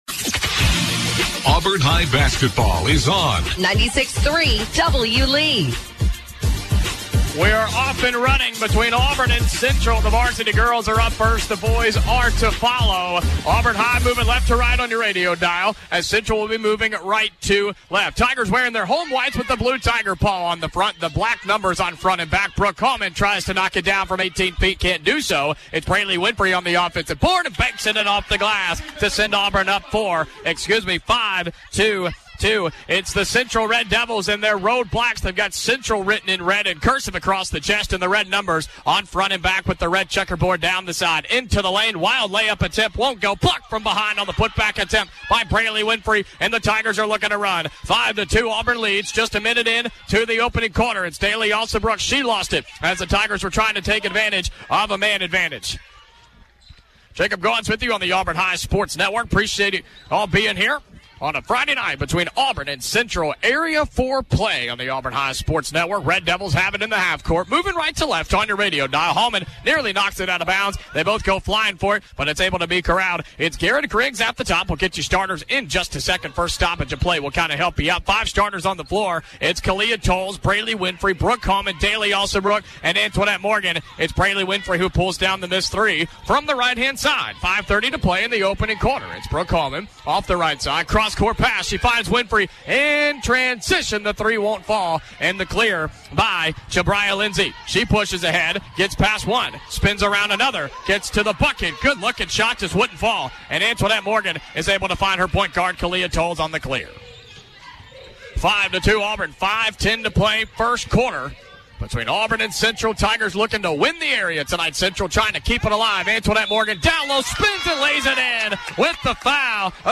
calls Auburn High's game against the Central Phenix City Red Devils. The Tigers won 47-38.